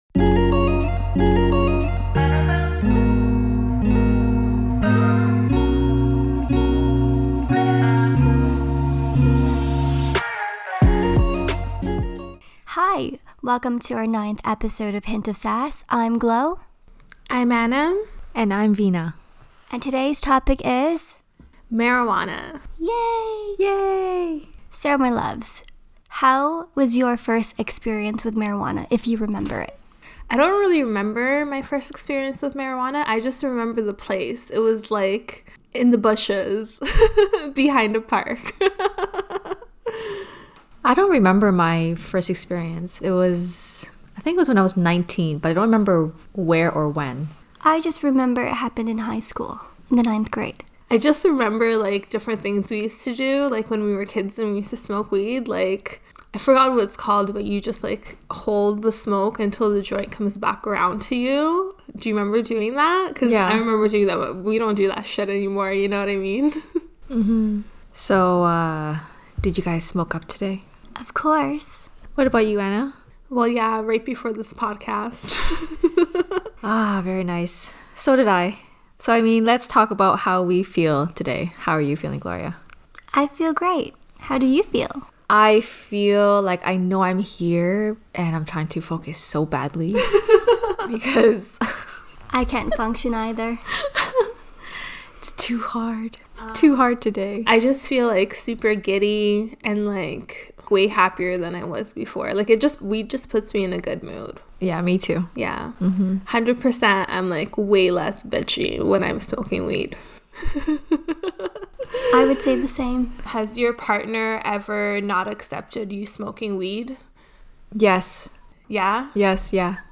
Play Rate Listened List Bookmark Get this podcast via API From The Podcast A trio of ladies discussing everyday topics with a hint of sass. Expect authentic, unfiltered and unapologetic discussion on everyday struggles.